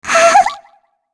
Shea-Vox_Happy1_kr.wav